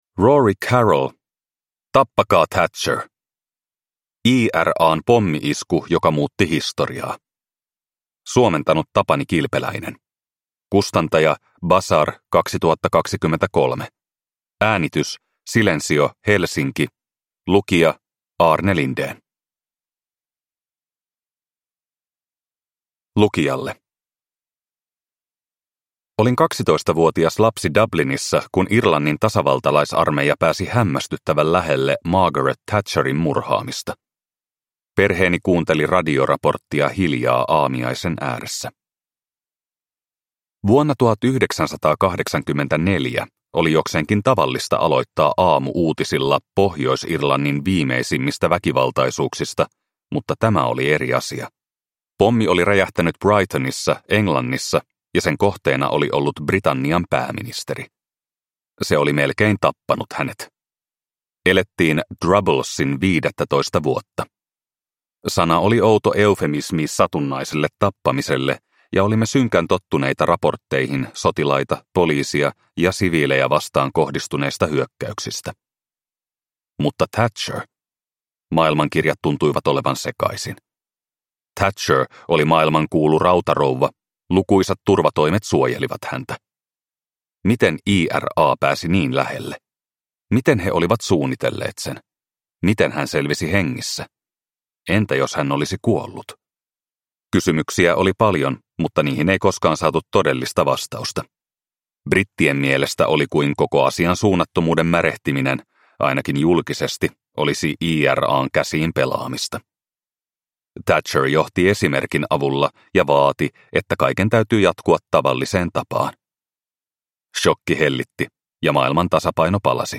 Tappakaa Thatcher! – Ljudbok – Laddas ner